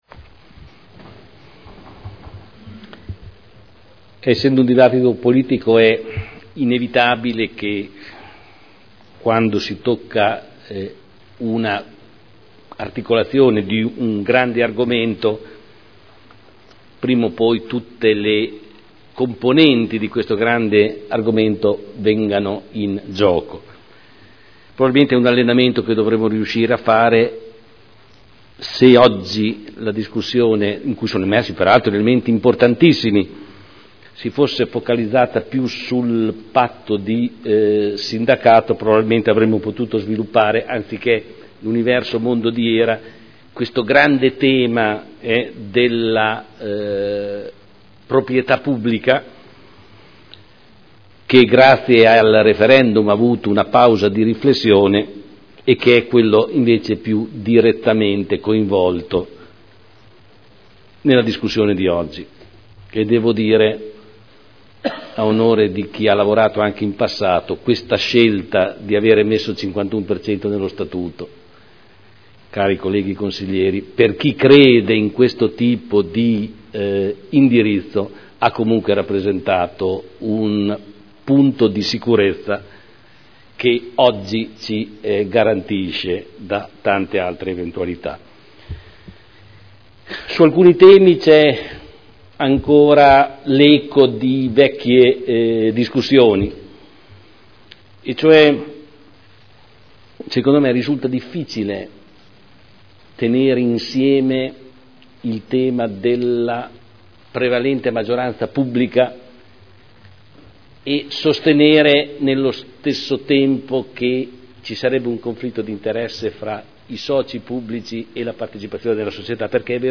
Seduta del 12 dicembre Proposta di deliberazione Contratto di sindacato di voto e di disciplina dei trasferimenti azionari fra i soci pubblici di Hera S.p.A. Dibattito